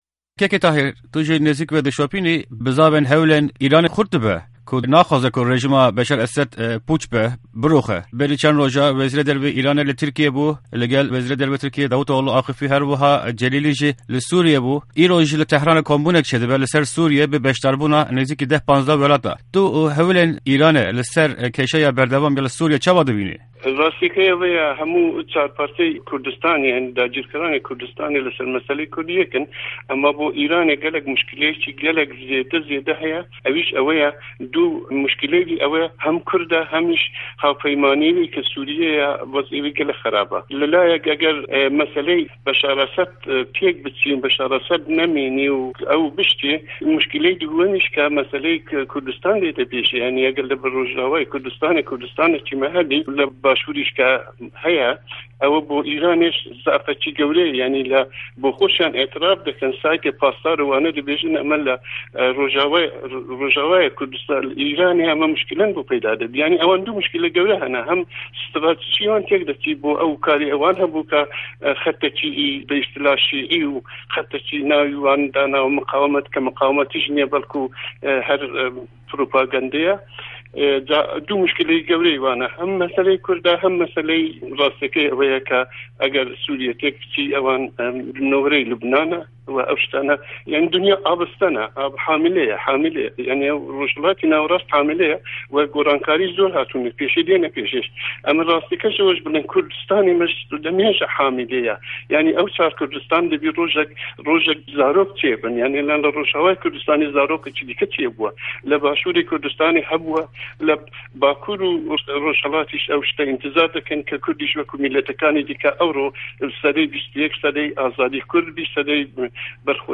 Hevpeyvîn_KT